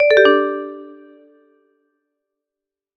mention_received.ogg